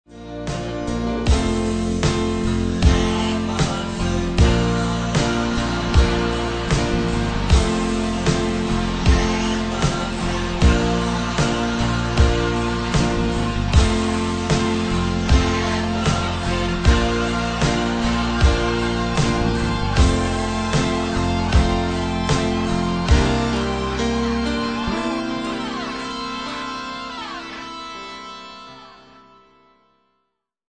Lo-Fi Sound-Quality